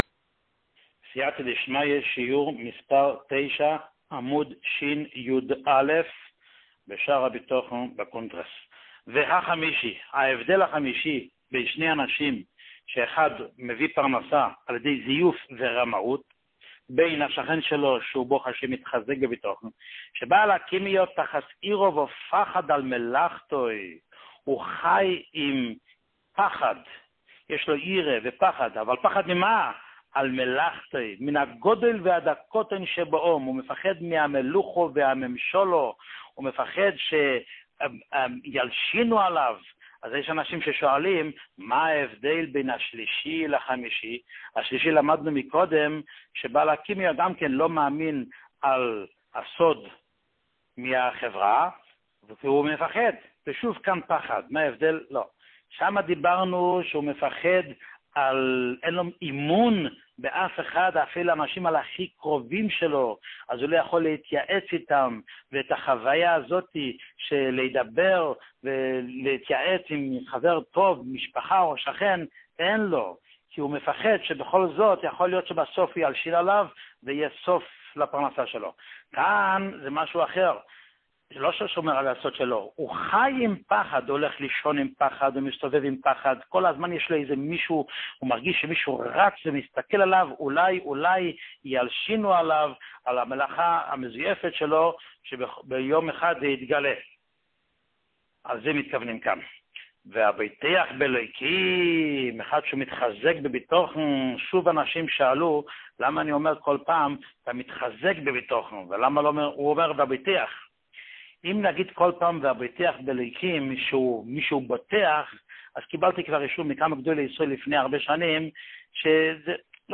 שיעור מספר 9